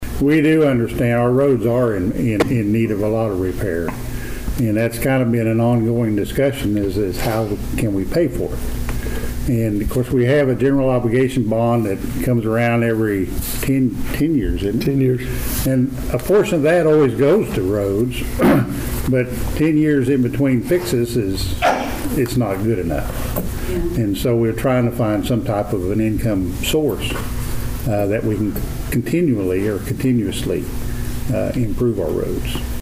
Also at the meeting, Council received citizen imput on certain topics, one being the state of roads in Dewey, particularly the westside of town.
Mayor Tom Hays addressed this concern.